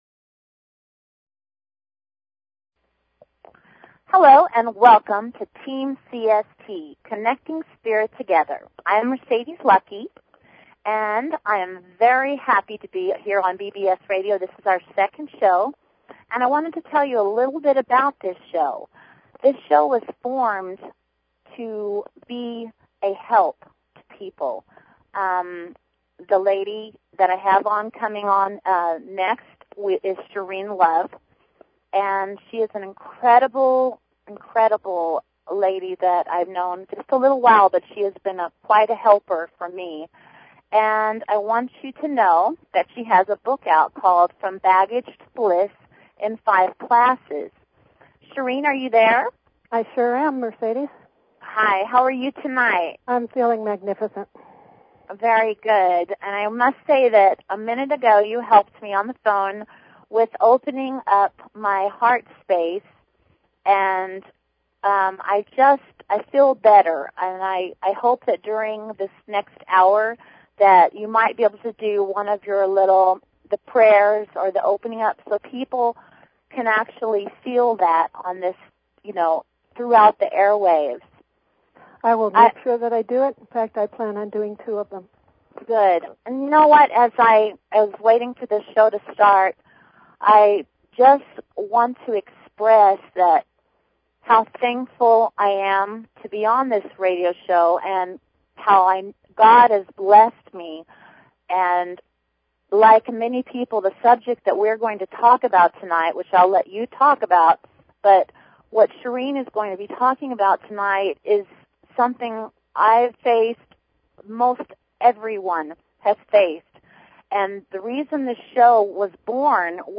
Talk Show Episode, Audio Podcast, Connecting_Spirit_Together and Courtesy of BBS Radio on , show guests , about , categorized as